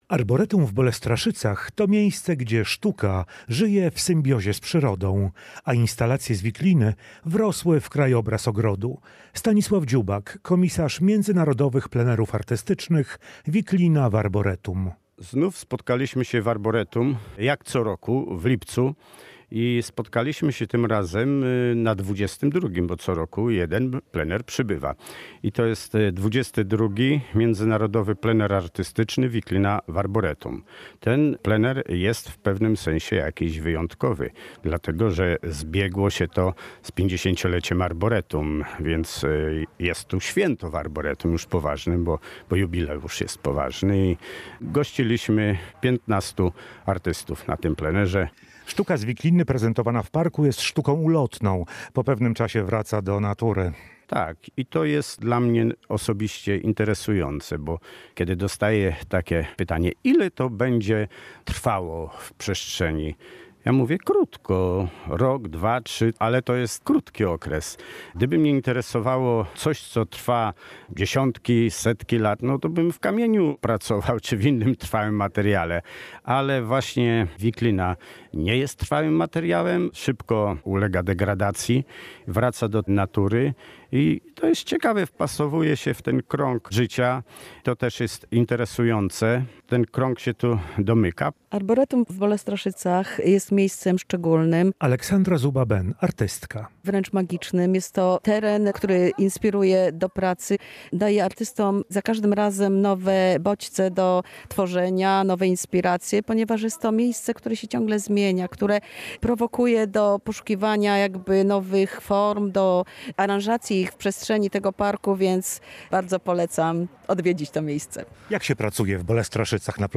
Kolekcja Arboretum w Bolestraszycach powiększyła się o 16 nowych instalacji z wikliny. Wernisażem prac uczestników zakończył się dwutygodniowy XXII Międzynarodowy Plener Artystyczny „Wiklina w Arboretum 2025”.